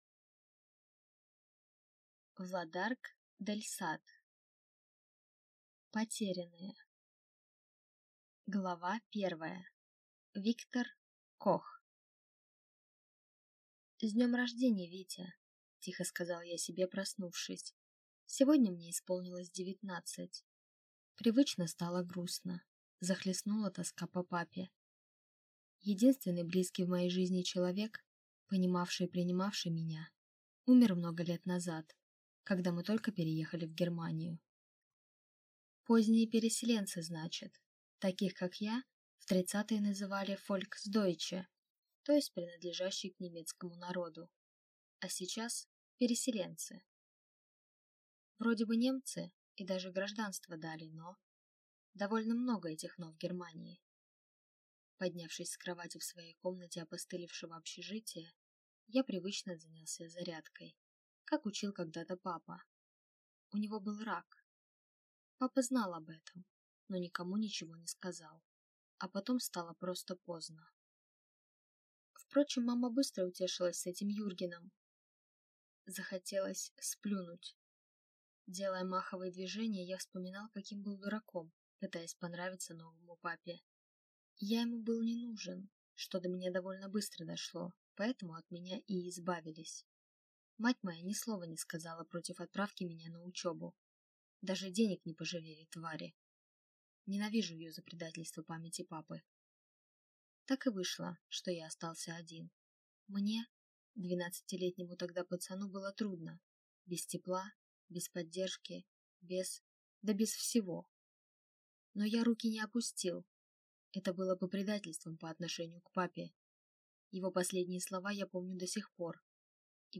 Аудиокнига Потерянные | Библиотека аудиокниг